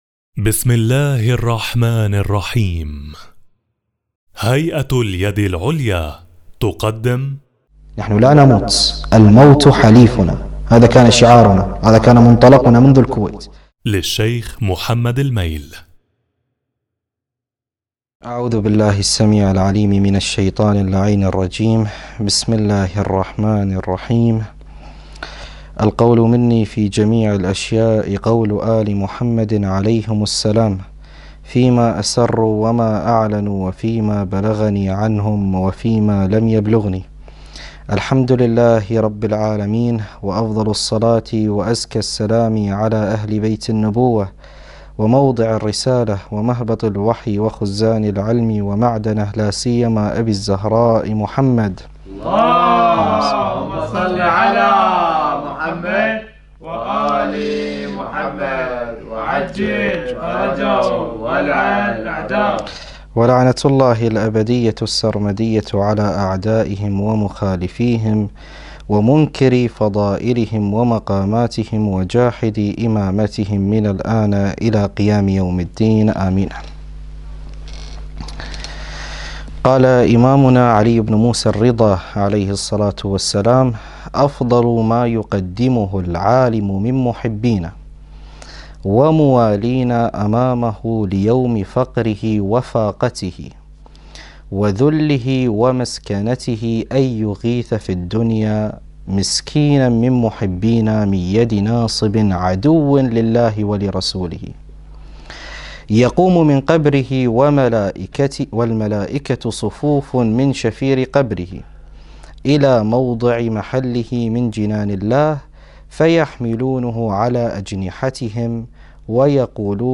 مكان التسجيل: لندن، المملكة المتحدة